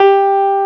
CLAV2SFTG4.wav